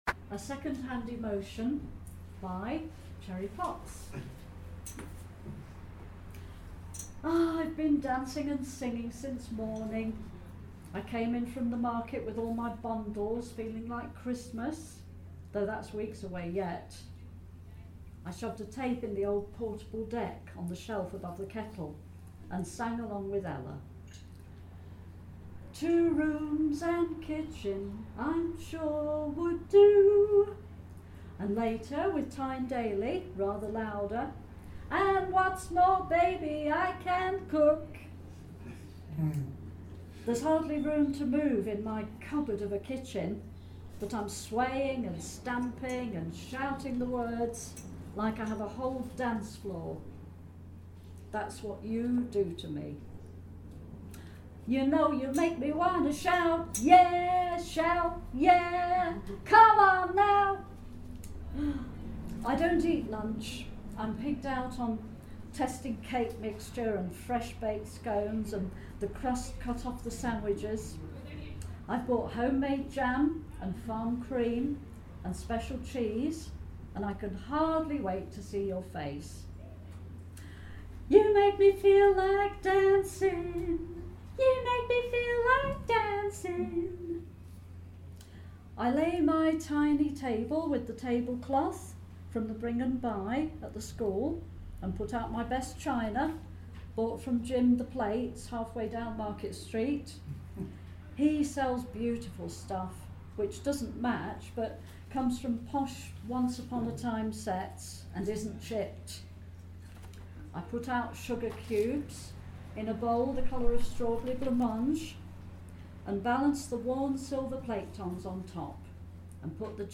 A collection of recordings of me (or actors) performing my stories live to audiences.